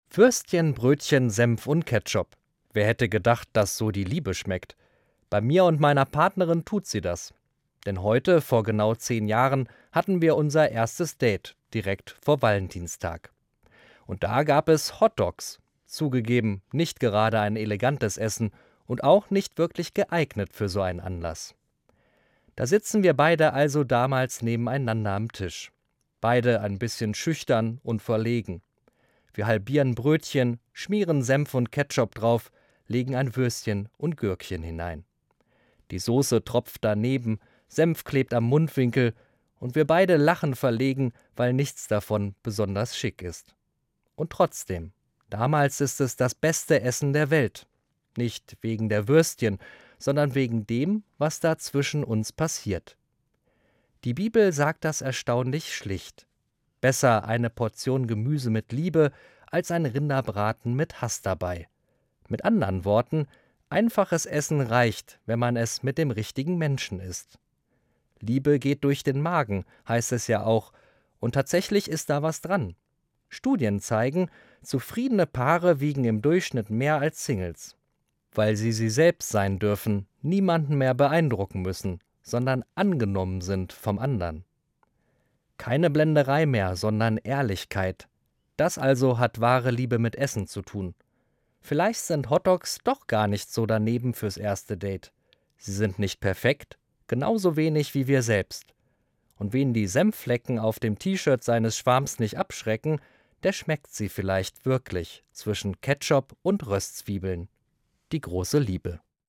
Evangelischer Pfarrer, Kassel